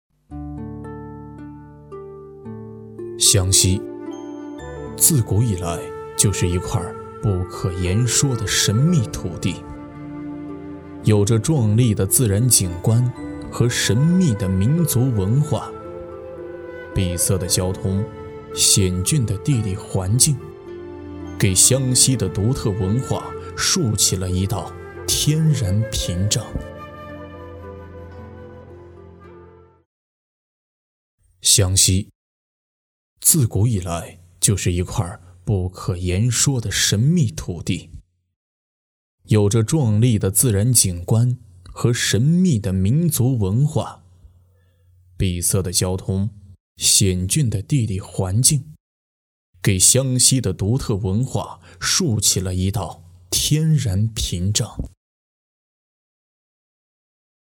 • 24专业男声10
纪录片-沉稳自然